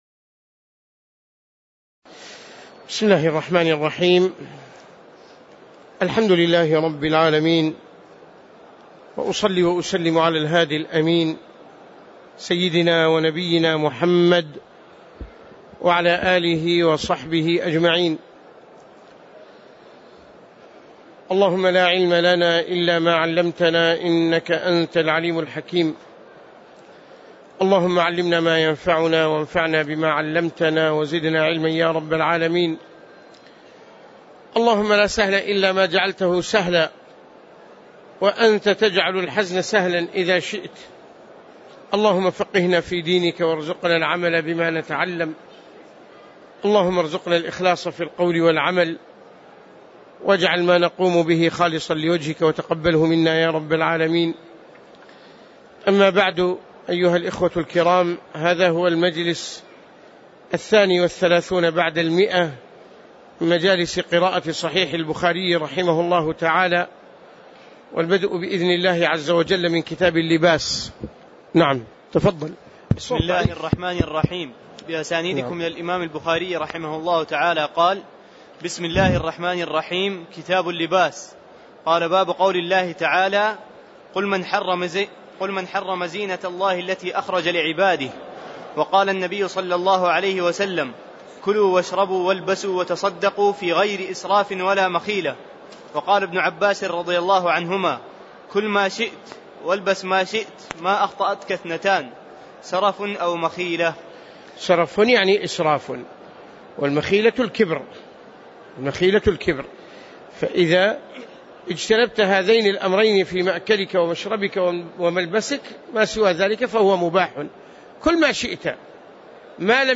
تاريخ النشر ٧ رمضان ١٤٣٨ هـ المكان: المسجد النبوي الشيخ